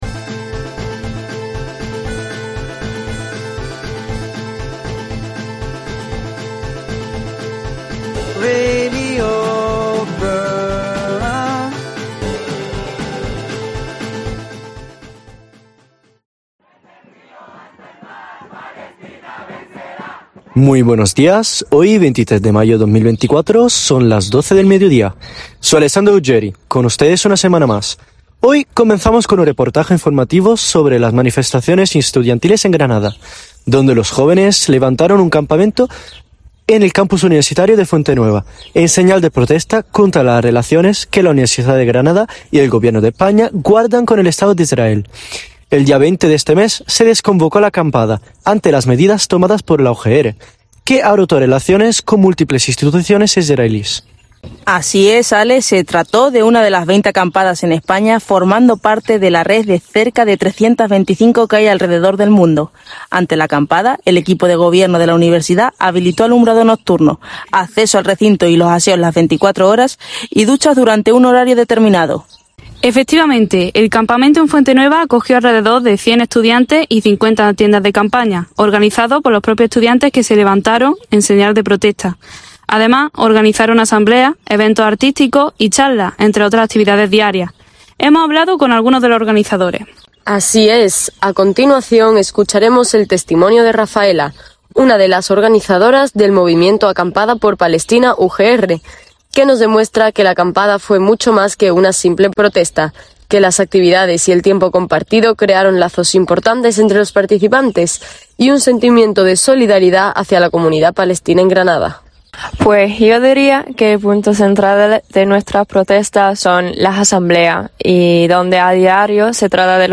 Para saber más de la acampada que tuvo lugar en Granada y conocer testimonios de algunos estudiantes que participaron en ella les invitamos a escuchar nuestro reportaje al respecto.